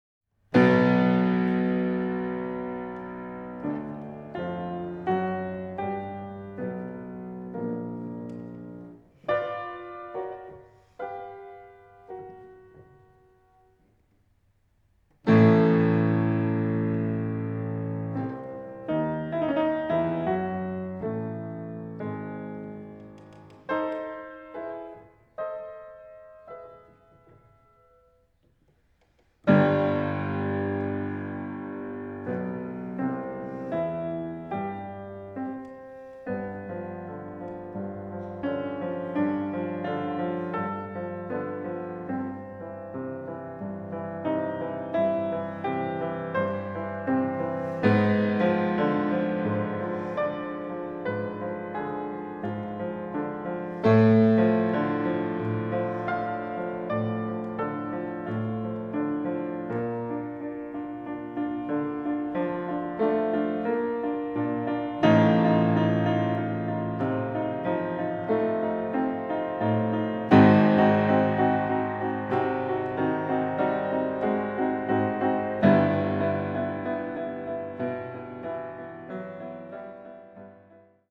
fortepiano